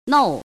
怎么读
nòu